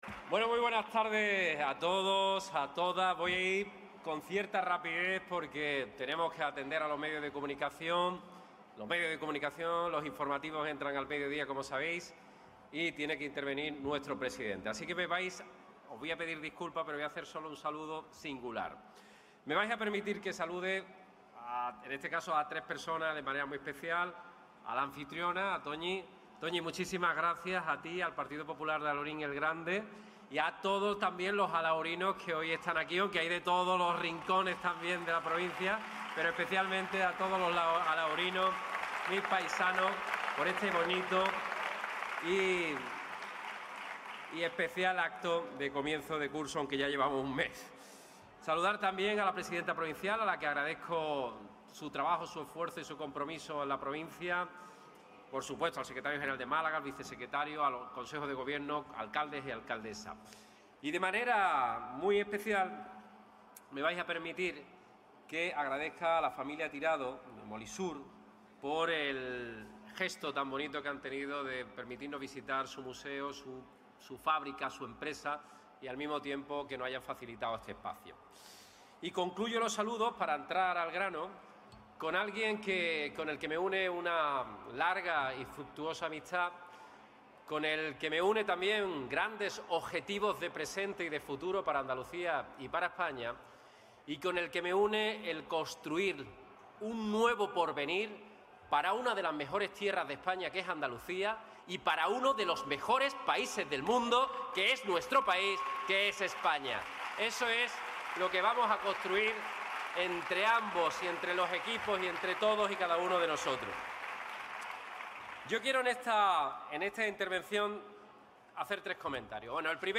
El líder del PP y de la oposición, Alberto Núñez Feijóo, abrió el curso político del PP en la localidad malagueña de Alhaurín El Grande.